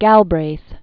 (gălbrāth), John Kenneth 1908-2006.